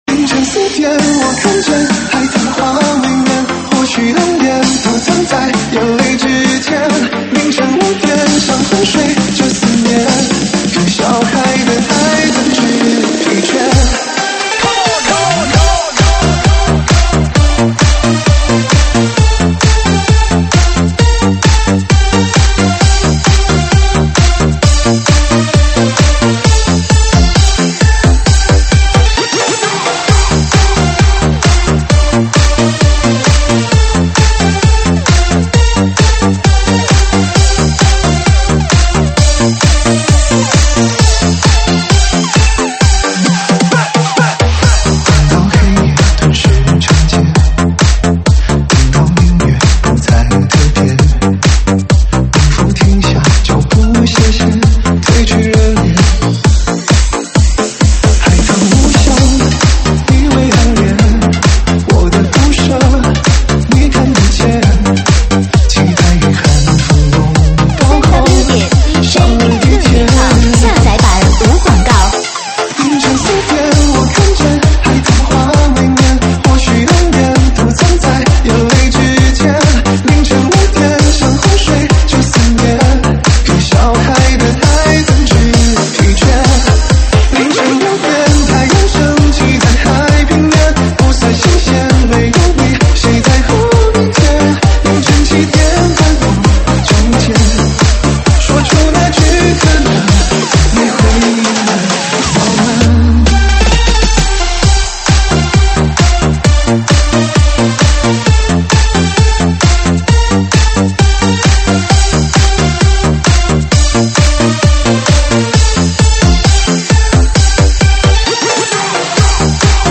(现场串烧)